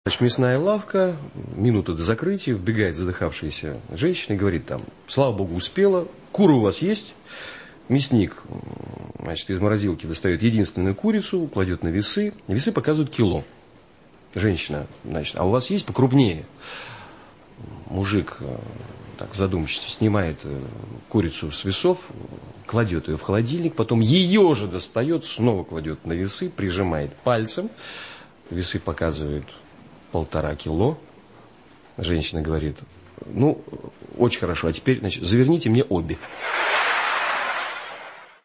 Звуки анекдотов